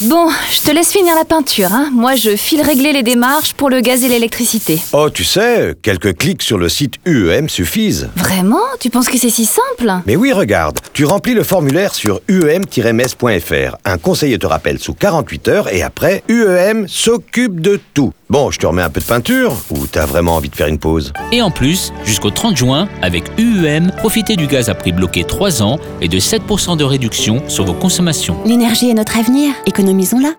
Message radio de la nouvelle campagne UEM by Sybillance studio.
Campagne radio UEM déménagement